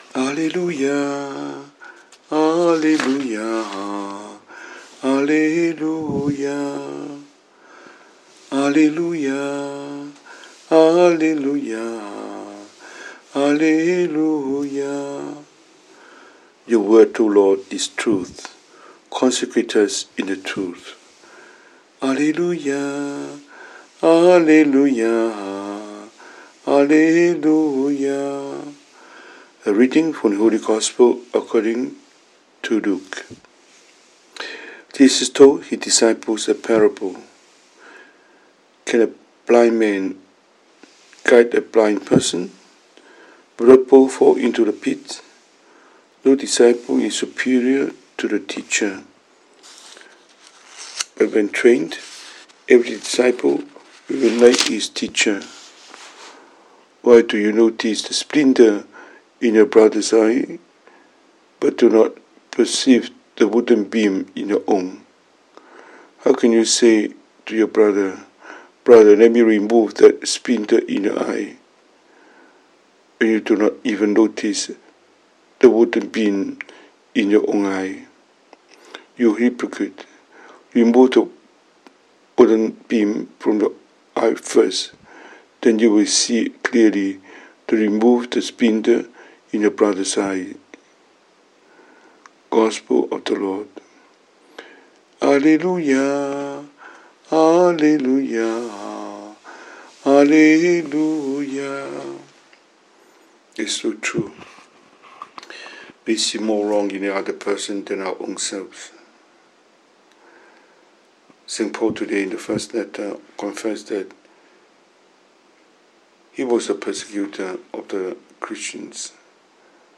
神父講道